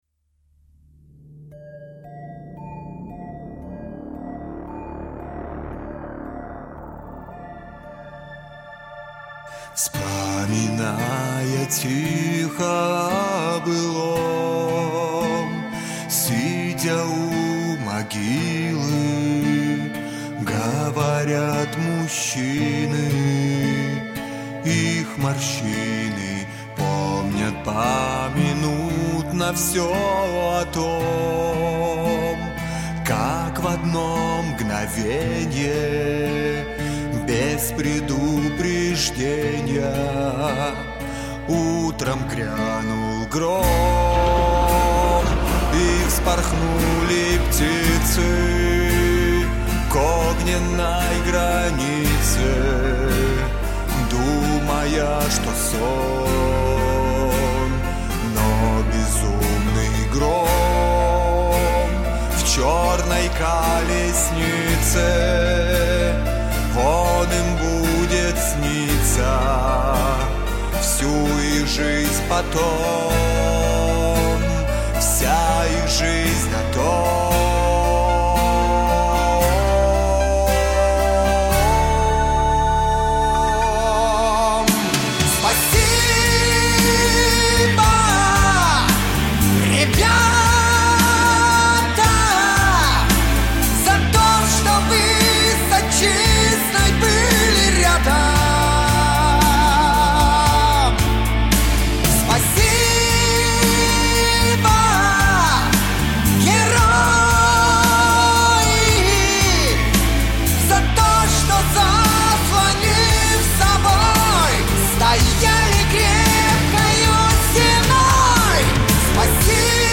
Конечно, можно было сделать свой голос чуть громче.